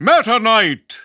The announcer saying Meta Knight's name in English and Japanese releases of Super Smash Bros. Brawl.
Category:Meta Knight (SSBB) Category:Announcer calls (SSBB) You cannot overwrite this file.
Meta_Knight_English_Announcer_SSBB.wav